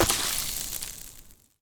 etfx_explosion_acid.wav